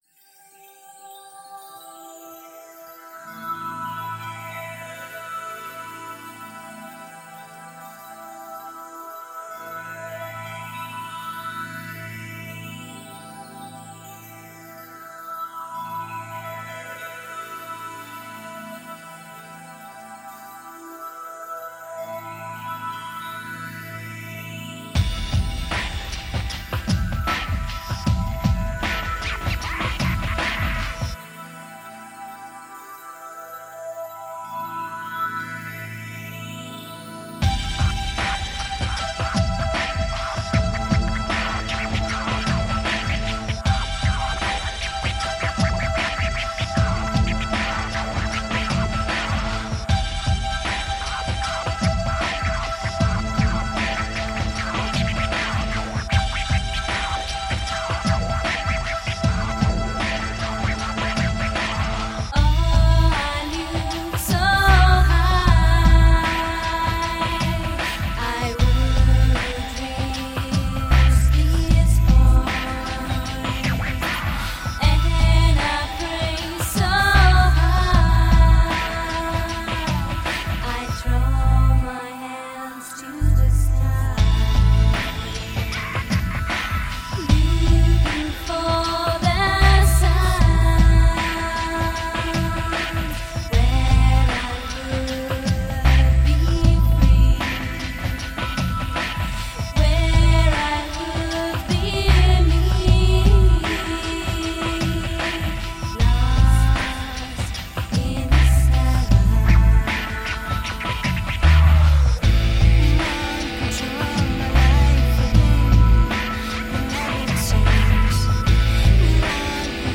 Groovy, french downtempo electro-rock songs.